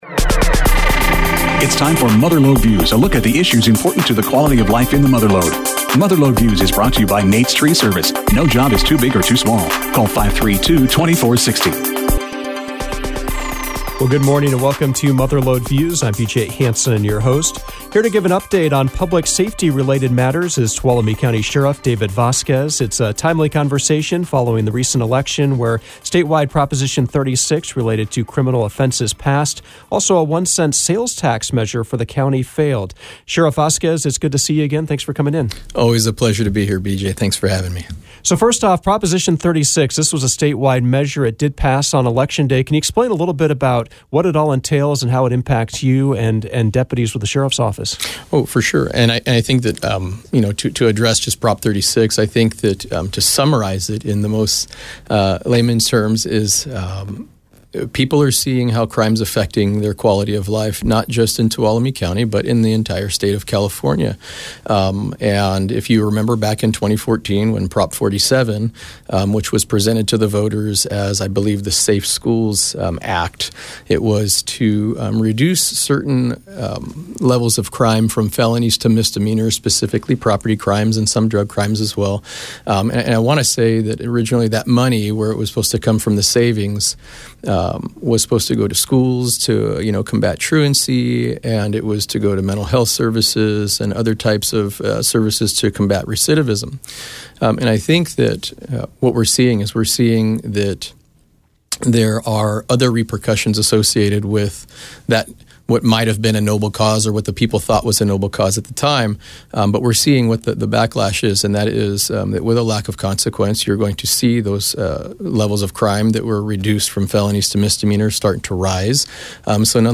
The guest was Tuolumne County Sheriff David Vasquez.